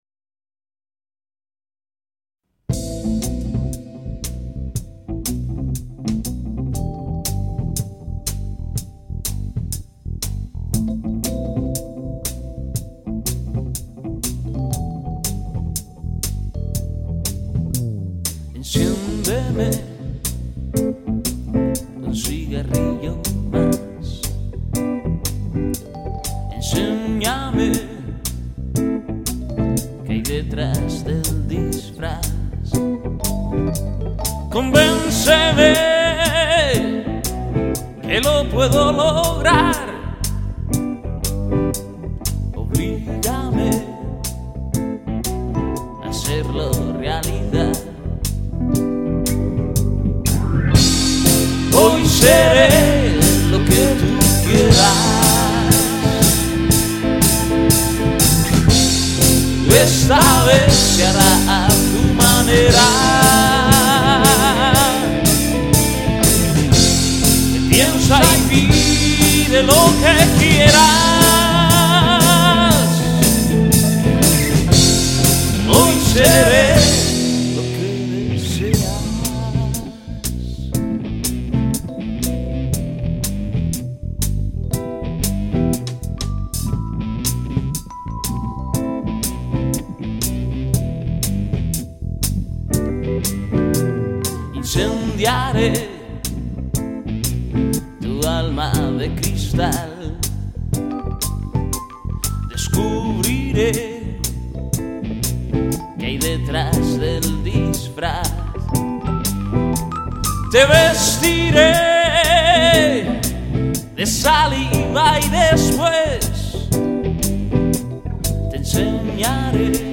Un grupo formado por cinco componentes